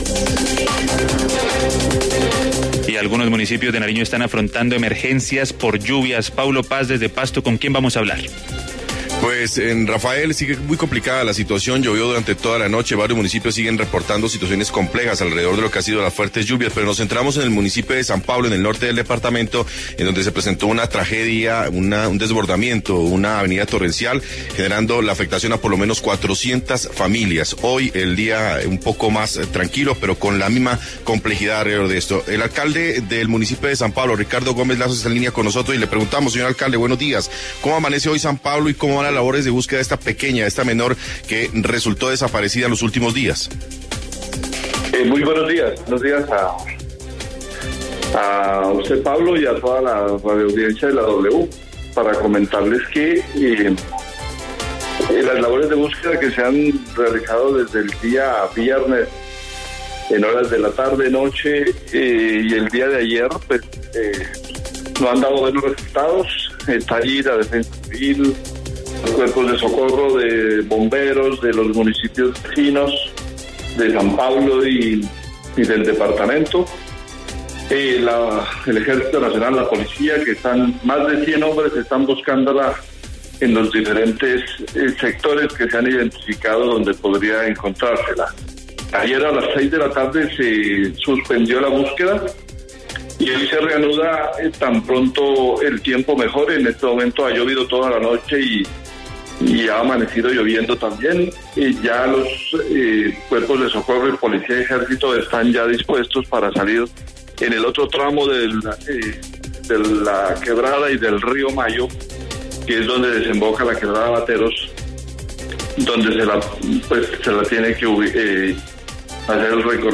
Ricardo Gómez, alcalde del municipio de San Pablo, Nariño, habló en W Fin de Semana sobre la tragedia.
En el encabezado escuche la entrevista completa con Ricardo Gómez, alcalde del municipio de San Pablo, Nariño.